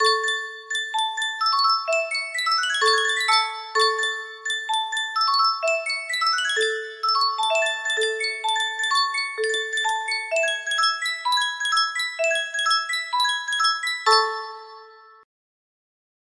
Sankyo Music Box - Tiger Rag RJW music box melody
Full range 60